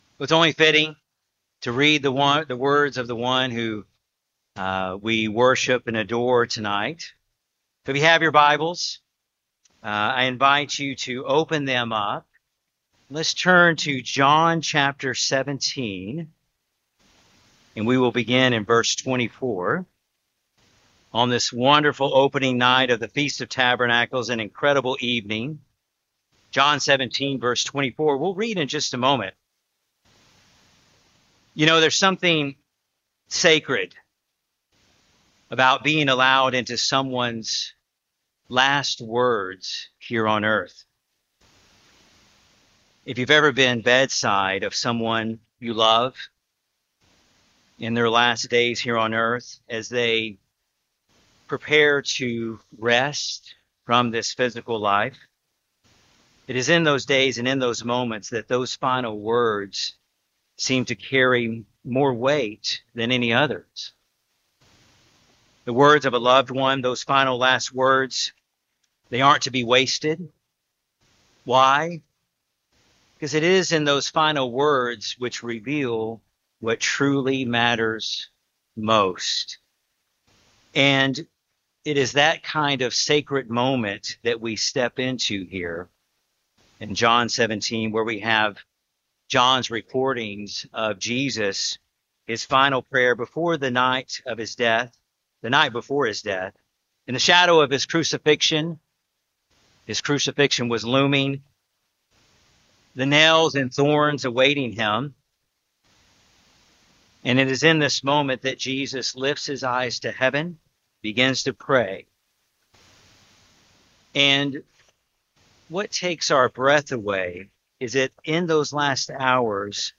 This sermon reflects on the profound moment in Gospel of John Chapter 17 when Jesus lifts His eyes to heaven and prays not for Himself, but for His disciples and all future believers, revealing His deep desire that they be protected, sanctified by truth, and ultimately share eternal life with Him and the Father. In the shadow of the coming crucifixion, Christ’s final prayer shows that His heart was filled with love for His people and His longing that they one day be with Him in glory.